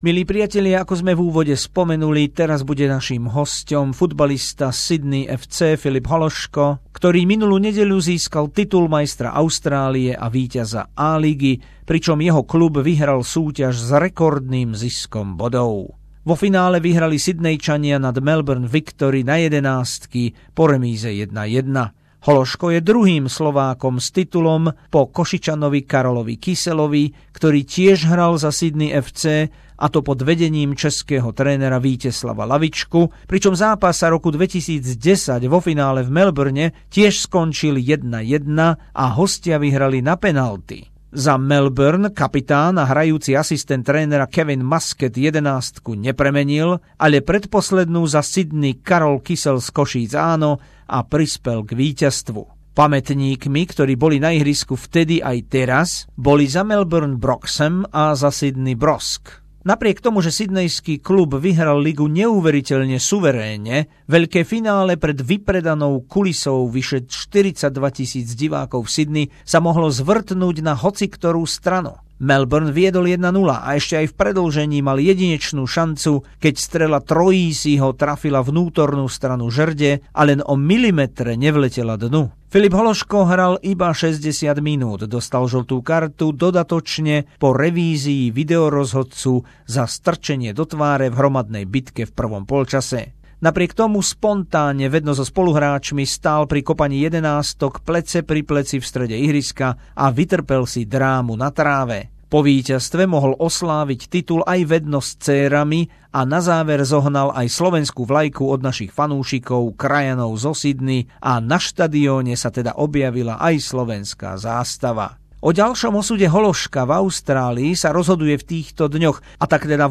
Rozhovor so slovenským futbalovým reprezentantom Filipom Hološkom zo Sydney FC po strhujúcom finále A-League 2017